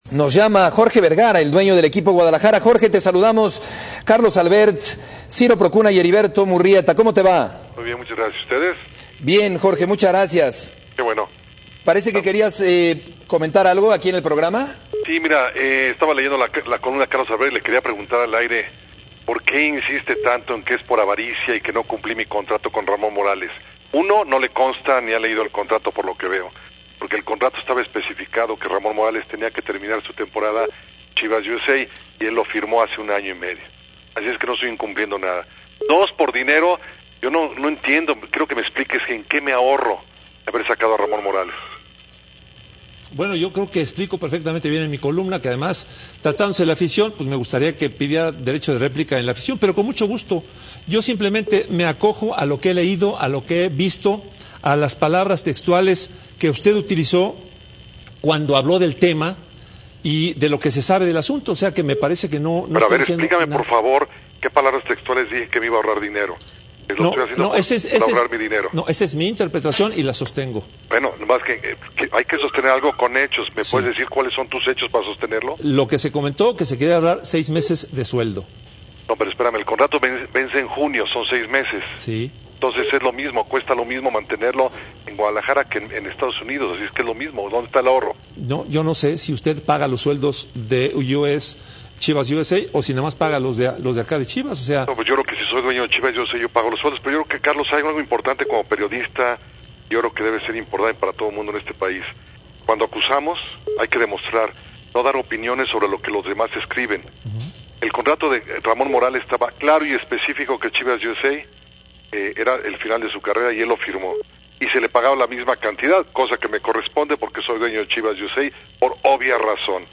Por cierto En el programa de Espn Radio Vergara hablo para reclamarle a Carlos Albert por una nota que escribio en un periodico... y Vergara volvio a abrir la boca para decir puras tonterias.